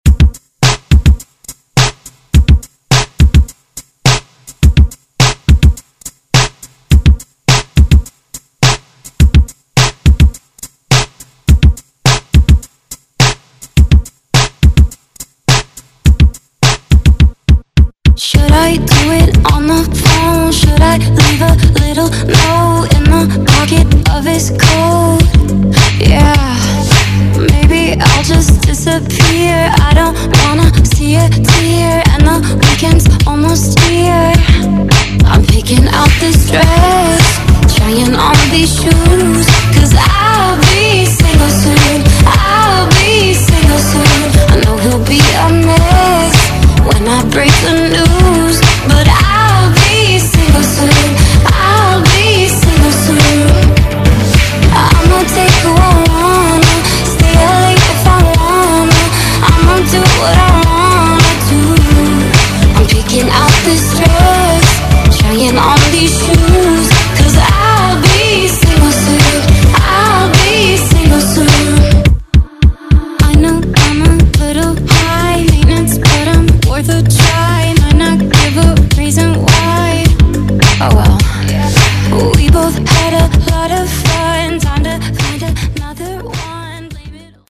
Genres: RE-DRUM , TOP40 Version: Clean & Dirty BPM: 115 Time